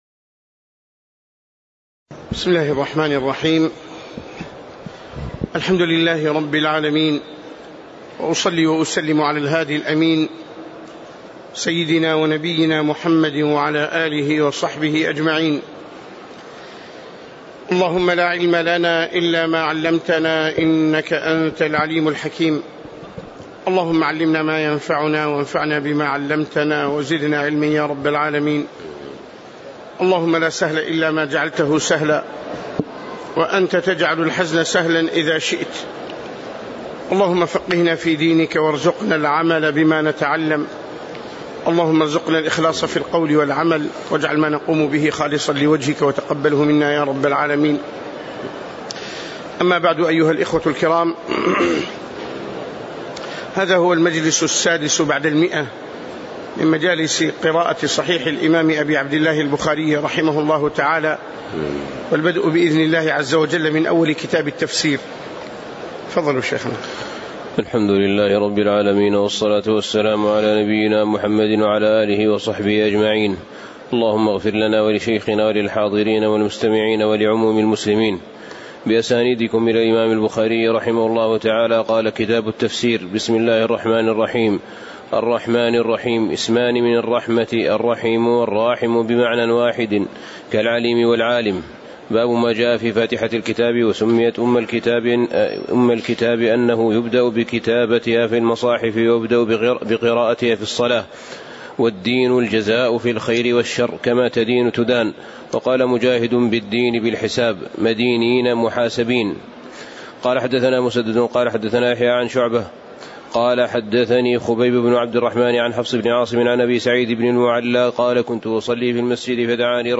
تاريخ النشر ٢٧ رجب ١٤٣٨ هـ المكان: المسجد النبوي الشيخ